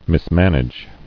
[mis·man·age]